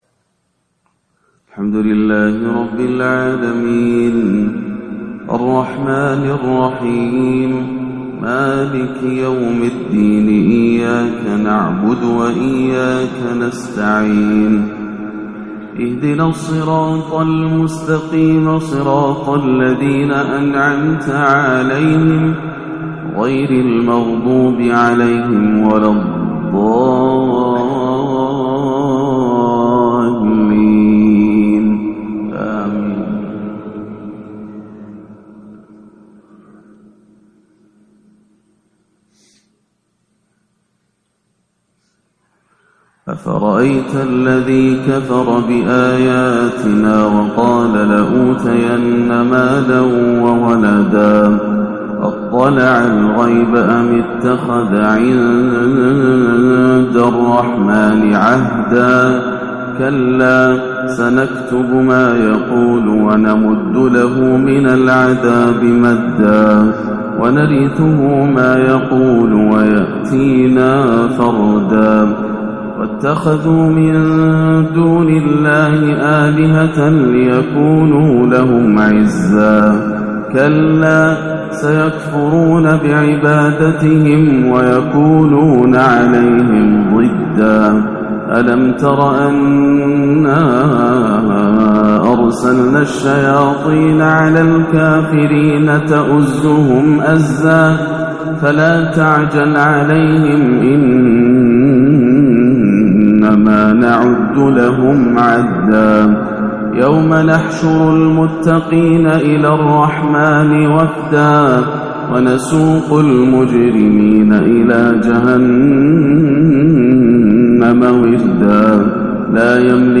فجر الخميس 3-4-1439هـ خواتيم سورتي مريم 77-98 و الكهف 107-110 > عام 1439 > الفروض - تلاوات ياسر الدوسري